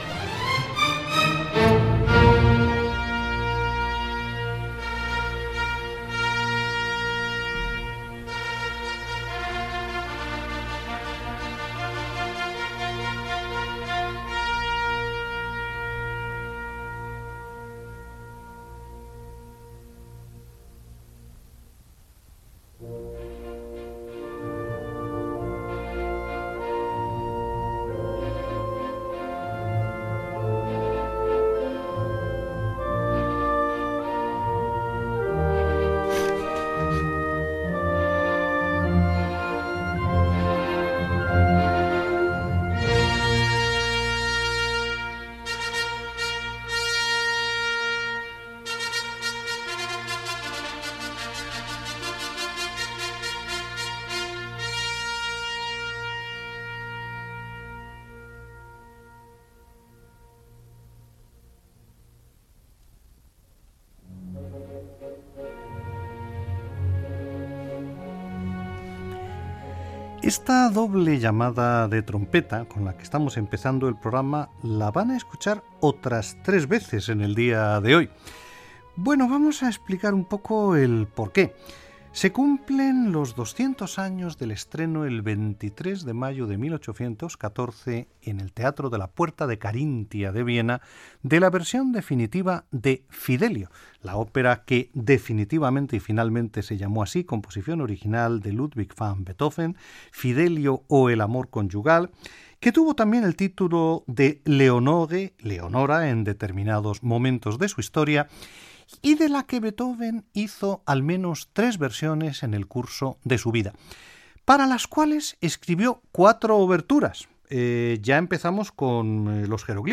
Música i comentari sobre l'obertura de l'òpera Fidelio de Ludwig van Bhetoven amb motiu dels 200 anys de l'estrena oficial de l'obra
Musical